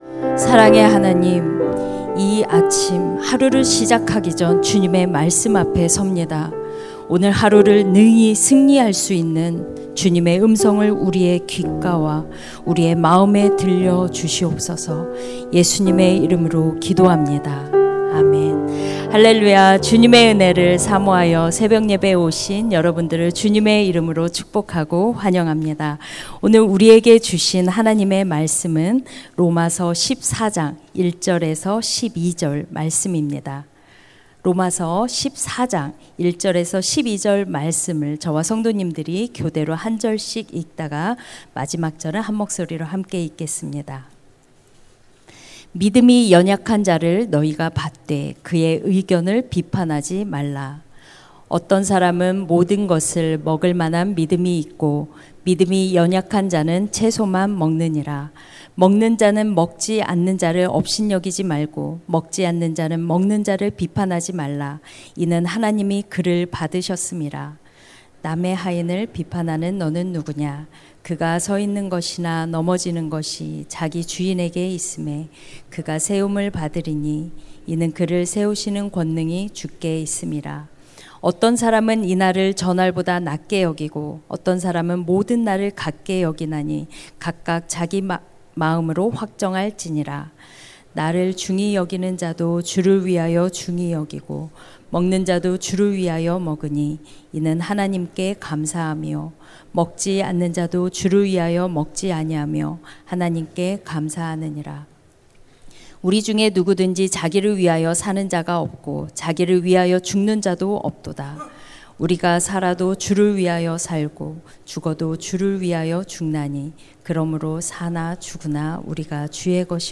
[새벽예배]